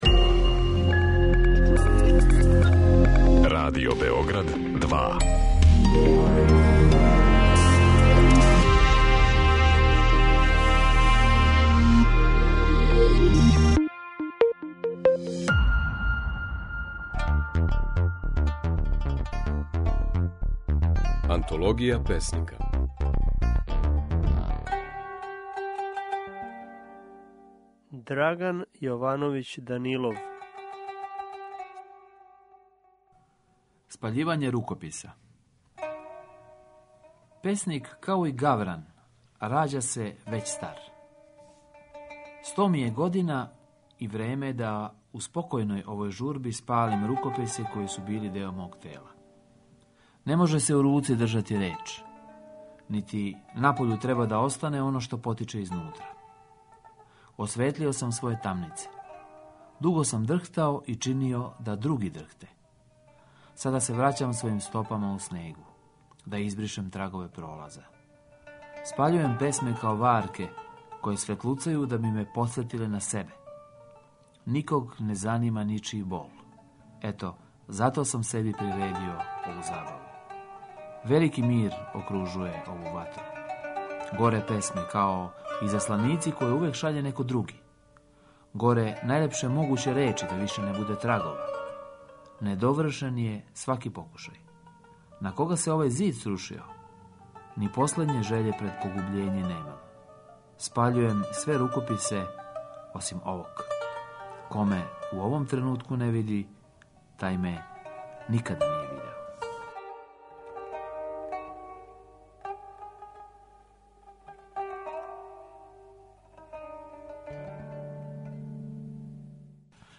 Можете чути како своје стихове говори песник Драган Јовановић Данилов.